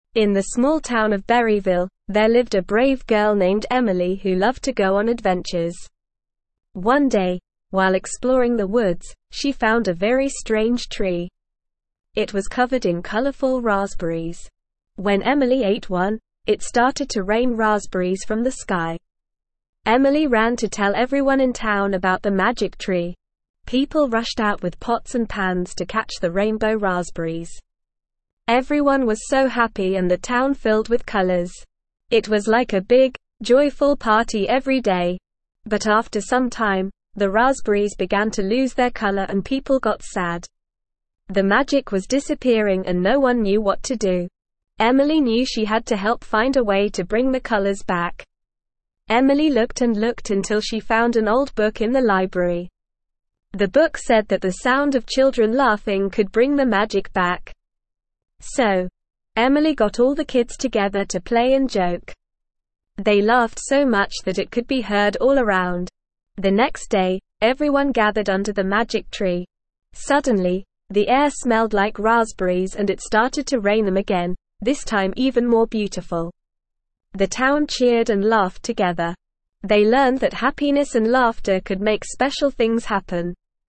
Normal
ESL-Short-Stories-for-Kids-Lower-Intermediate-NORMAL-Reading-The-Remarkable-Raspberry-Rain.mp3